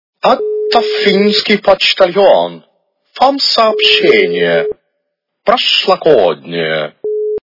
» Звуки » звуки для СМС » Звук для СМС - Это финский почтальон, Вам сообщение